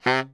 Index of /90_sSampleCDs/Giga Samples Collection/Sax/BARITONE DBL
BARI  FF C 2.wav